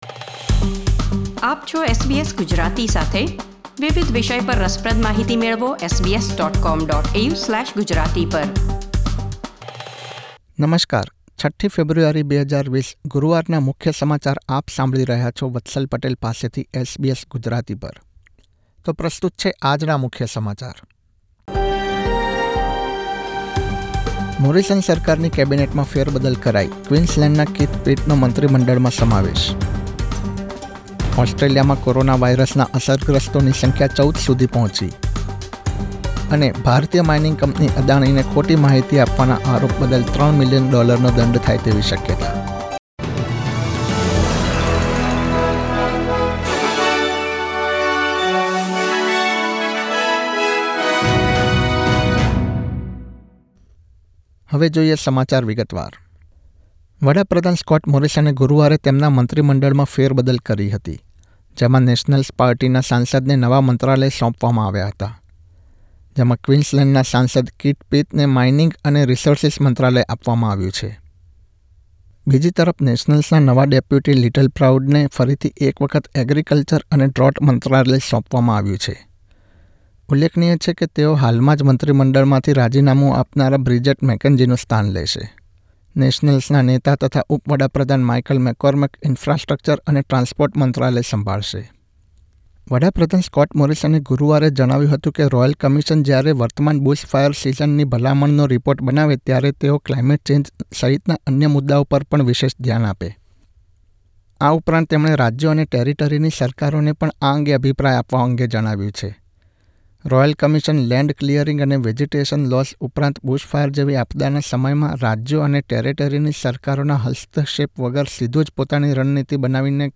SBS Gujarati News Bulletin 6 February 2020